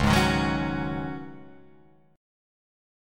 C#sus2sus4 chord